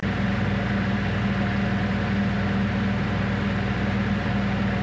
fan.mp3